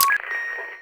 Intercom.wav